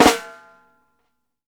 FLAM1     -L.wav